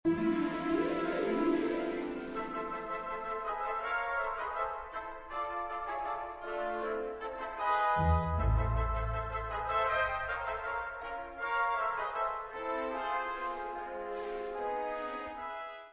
Īstā Mūzika MP3 formātā, kāda tā skan patiesībā